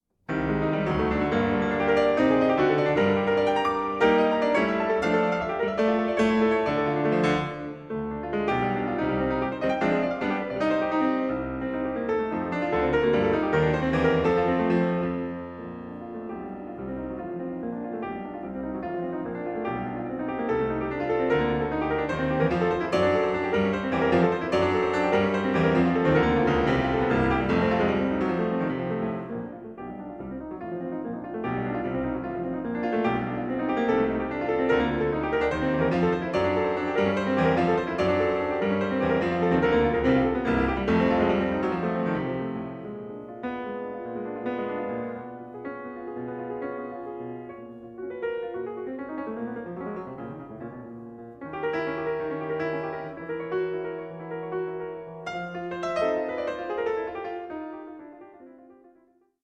Stereo
piano